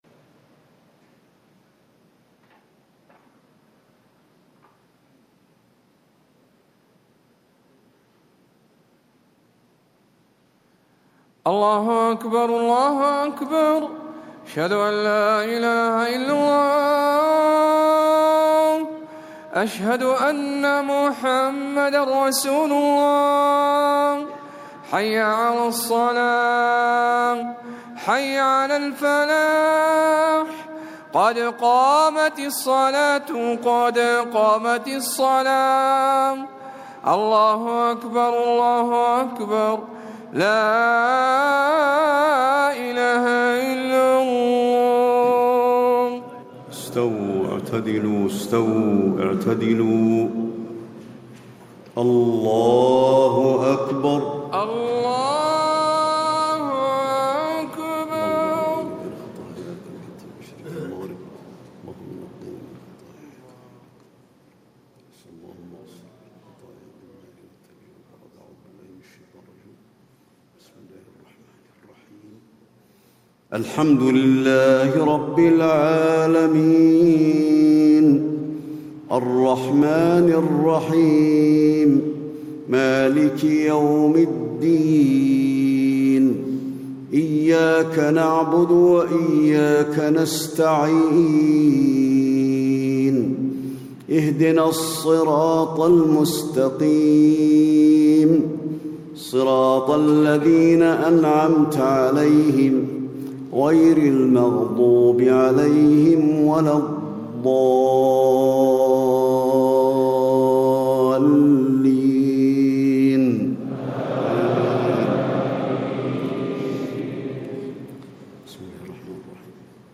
صلاة الجمعة ١١ ذو الحجة ١٤٣٦هـ سورتي العصر و الإخلاص > 1436 🕌 > الفروض - تلاوات الحرمين